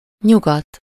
Ääntäminen
Etsitylle sanalle löytyi useampi kirjoitusasu: westen Westen Ääntäminen Tuntematon aksentti: IPA: /ˈʋɛs.tə(n)/ Haettu sana löytyi näillä lähdekielillä: hollanti Käännös Ääninäyte 1. nyugat Suku: n .